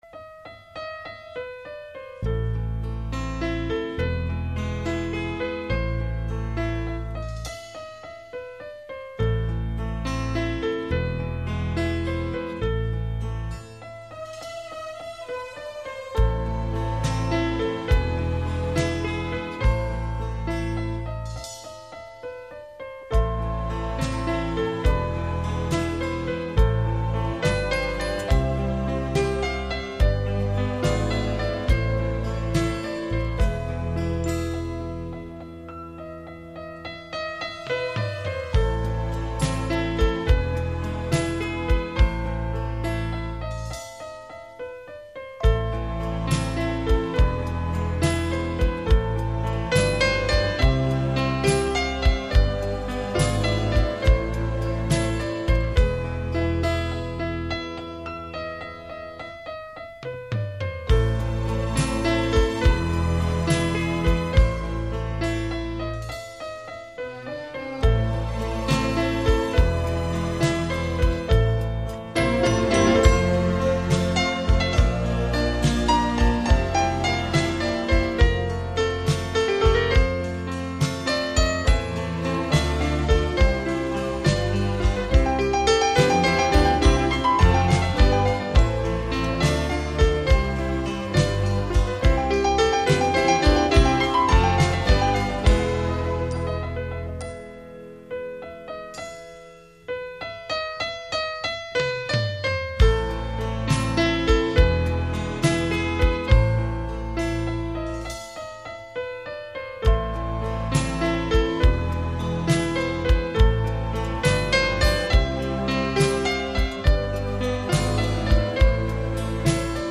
0264-钢琴名曲献给艾丽斯.mp3